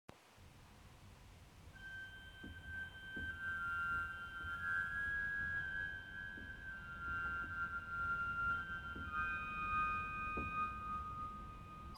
R6-Superoctave-2.mp3